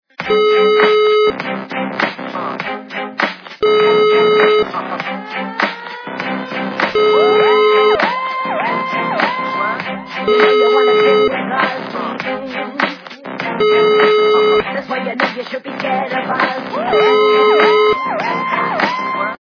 качество понижено и присутствуют гудки.